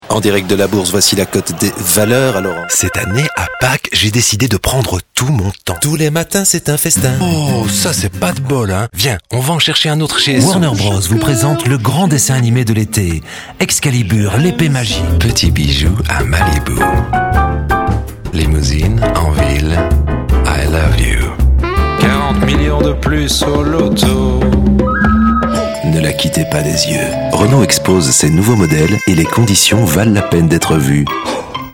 Voix-off pro français grave posée profonde
Sprechprobe: Werbung (Muttersprache):